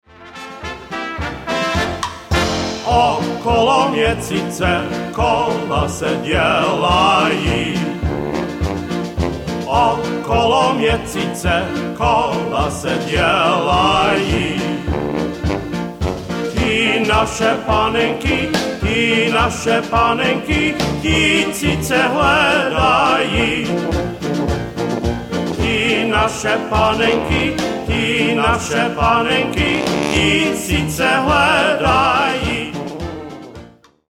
Czech Music in Wisconsin
piano
trumpet
tuba
accordion